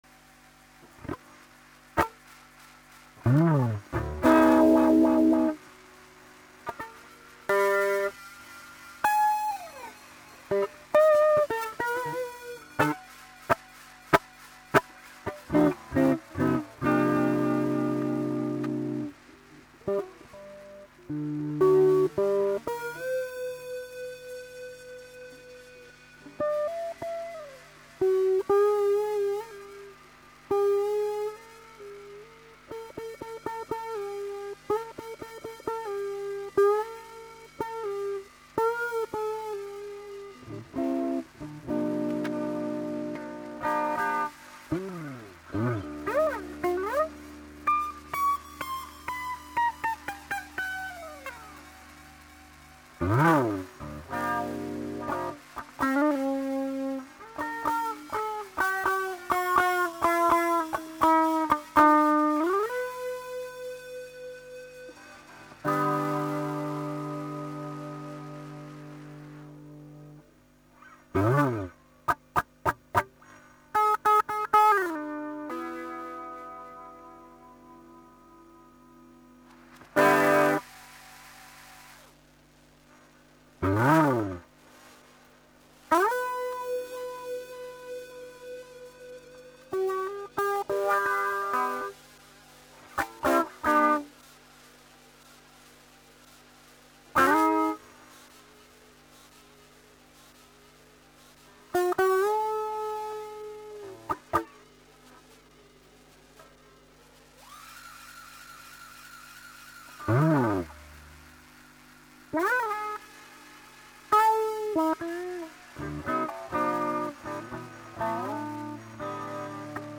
ぉ試し走行、消去用マグなしゃと